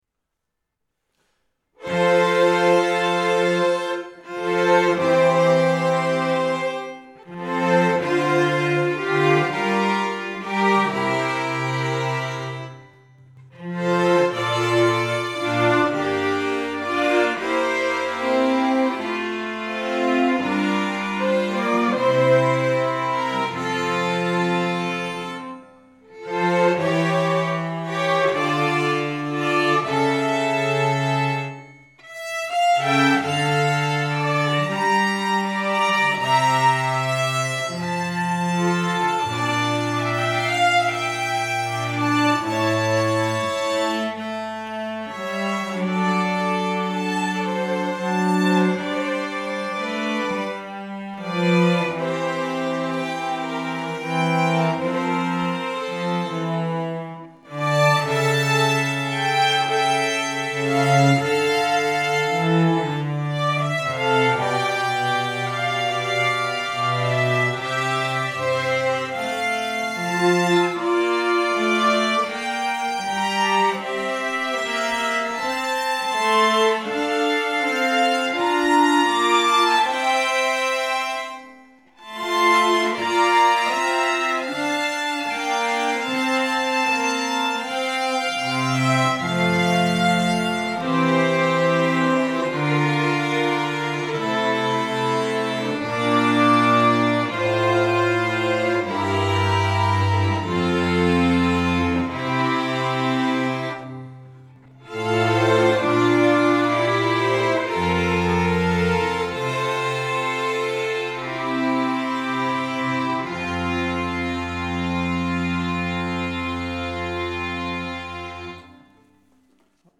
Here also are three examples of multi-track recording, with me playing all the instruments.
Handel, the Prelude to a concerto grosso by